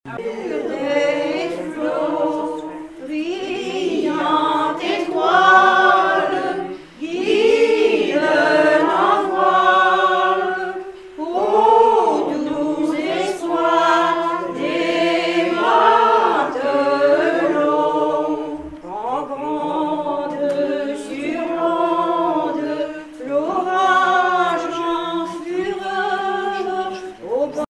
Club d'anciens de Saint-Pierre association
Cantiques maritimes
Genre strophique
Pièce musicale inédite